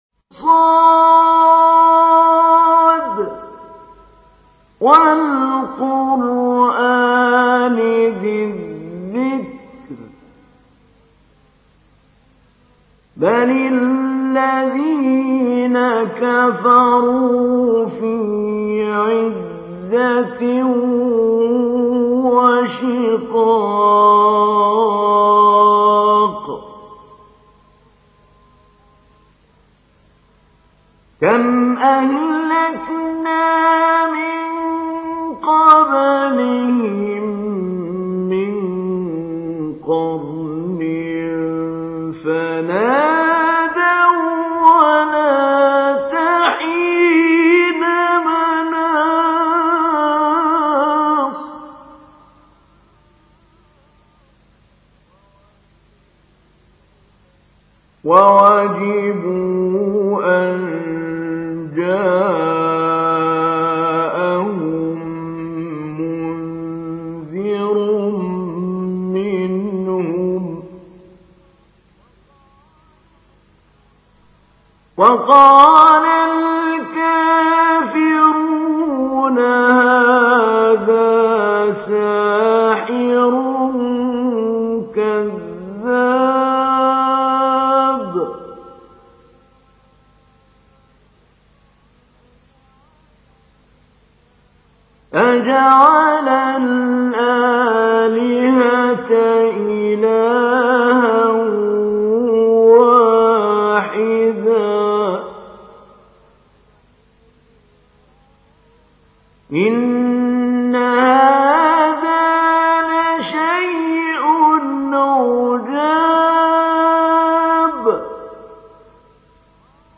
تحميل : 38. سورة ص / القارئ محمود علي البنا / القرآن الكريم / موقع يا حسين